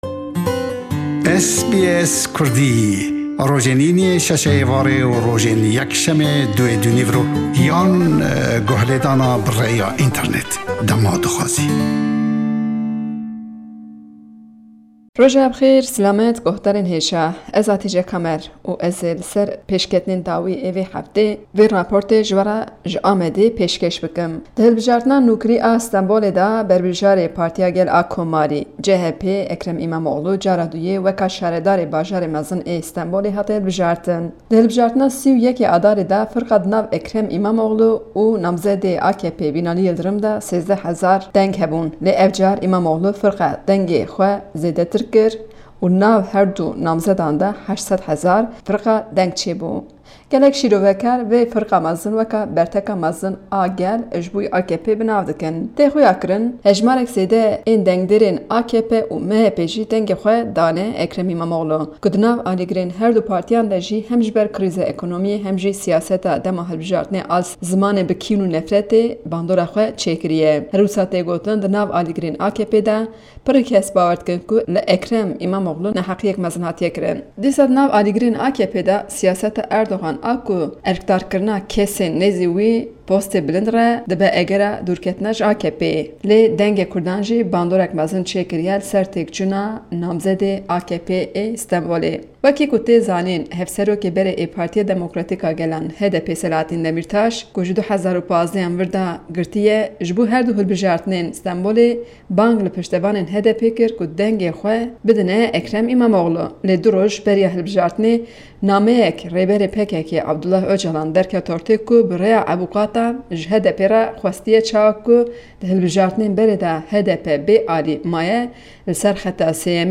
ji Diyarbekir li ser encama hilbijaritina şaredariya Stembolê ye. Herweha di raportê de behs li ser çûna Erdogan jibo civîna G20 ango Koma 20 ya ku li Japonê cî digire. Daxwaza cezakirina rojnamevaneke Kurd û rewşa rojnamevaniyê li Tirkiyê û babetên din di raporta ji Diyarbekir de hene.